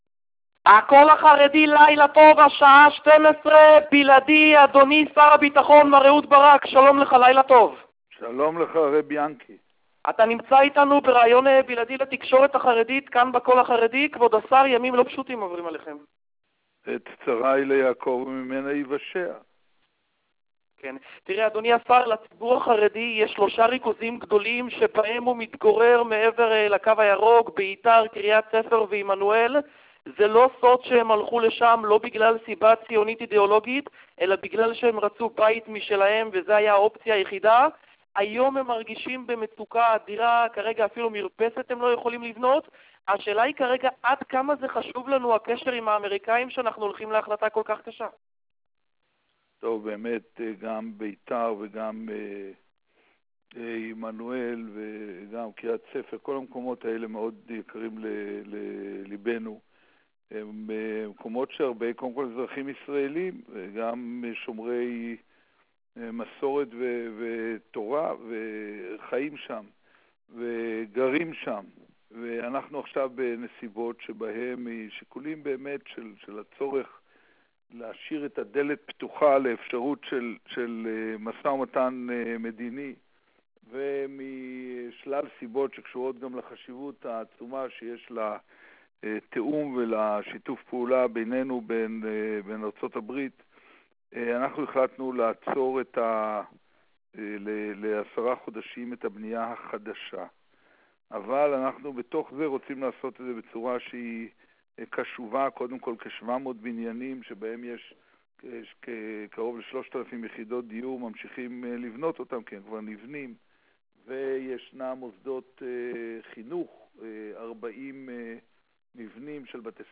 שר הביטחון בראיון:
"נשארו רק תשעה וחצי חודשים", מנחם שר הביטחון, אך מקפיד להישמע תקיף ונחוש כשהוא אומר "אנחנו הולכים לגייס את הכוחות ביחד לעמוד בזה".